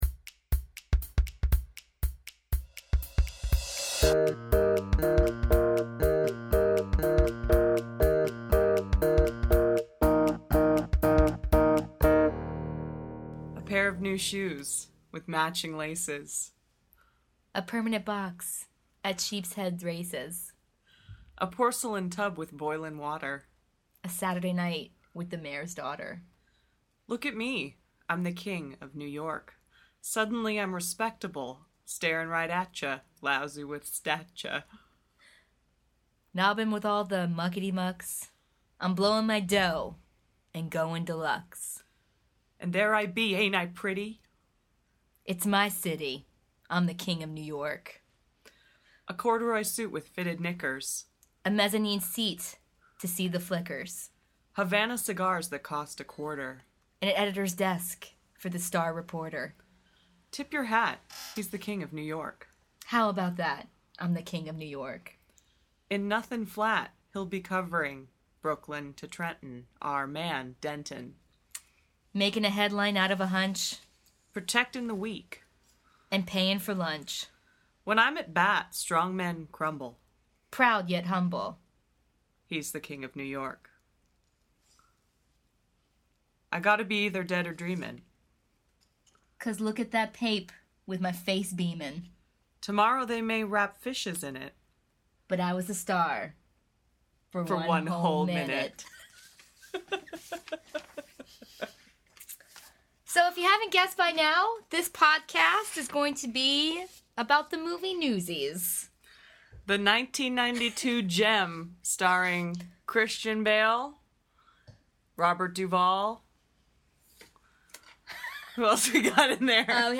Featuring special call-in guest